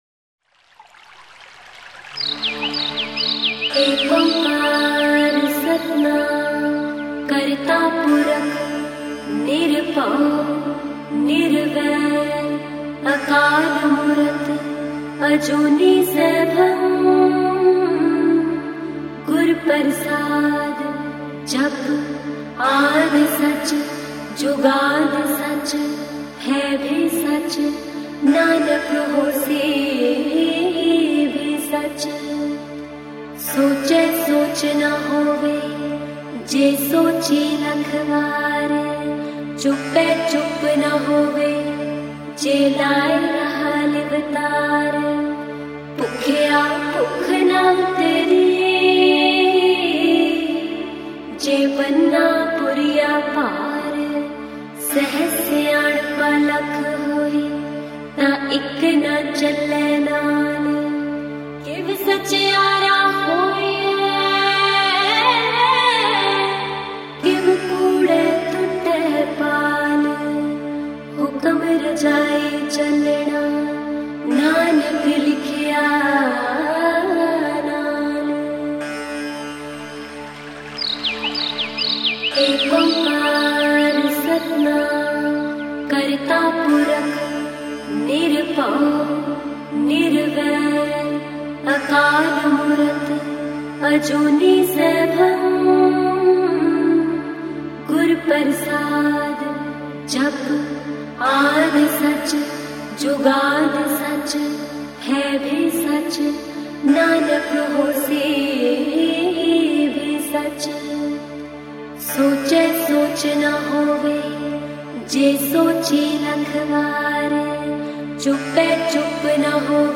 Sikh Song Album Info